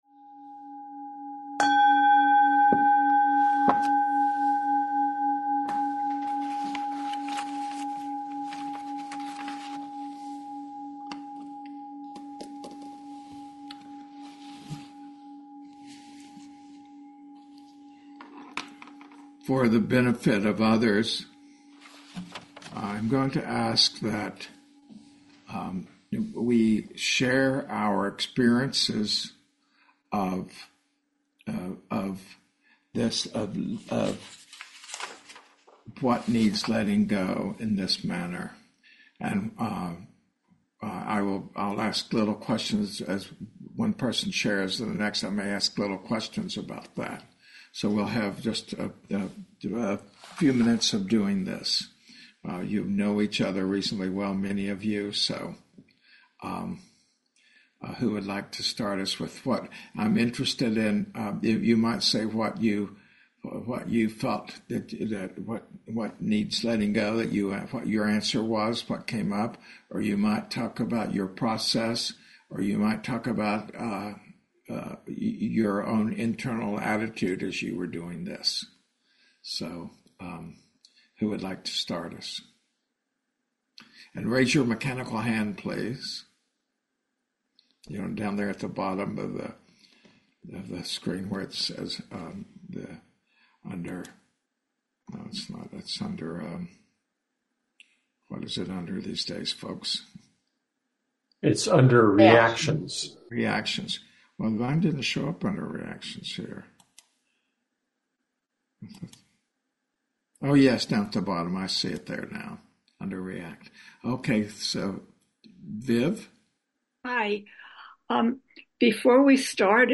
Walking Meditation Q&A